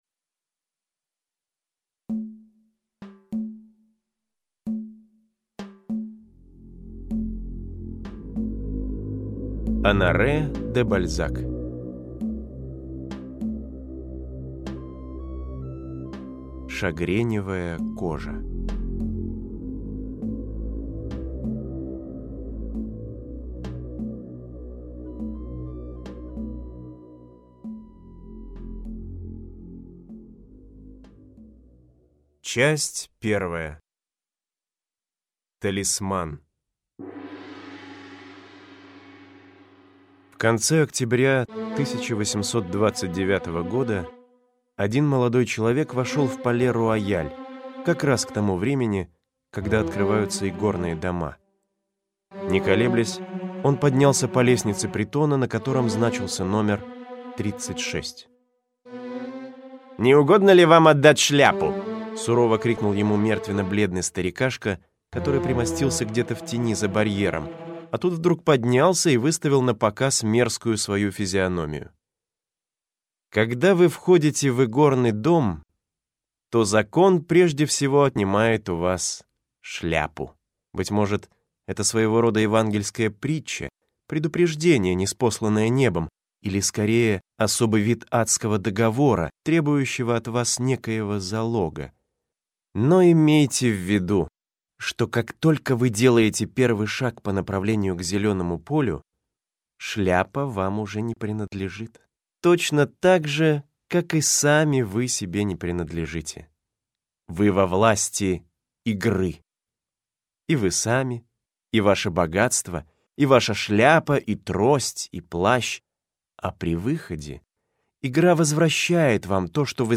Аудиокнига Шагреневая кожа (спектакль) | Библиотека аудиокниг